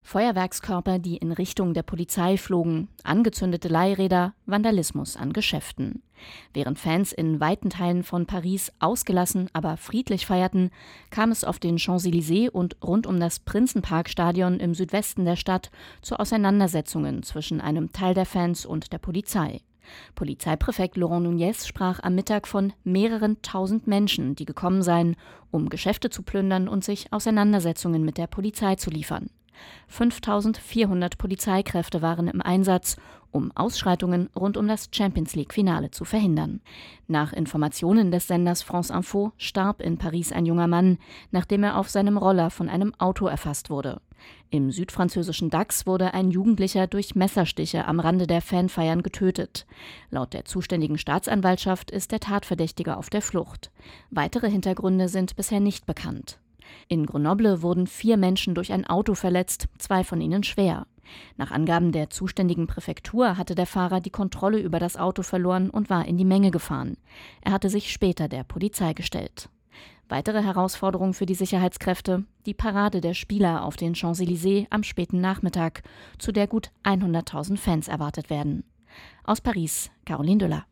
Hier der Bericht